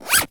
foley_zip_zipper_short_03.wav